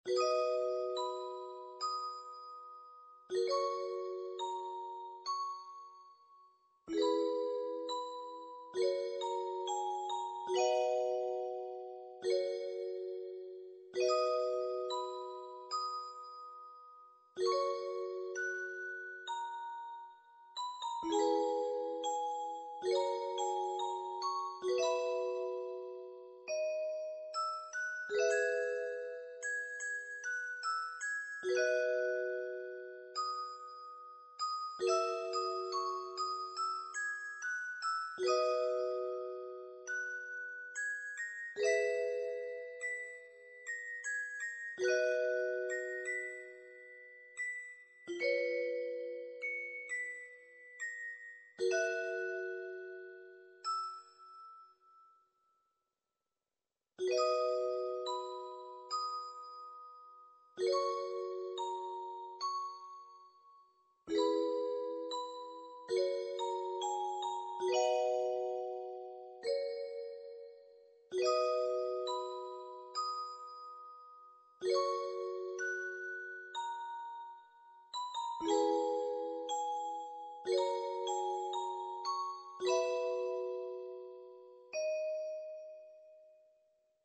EL-900で作成したものです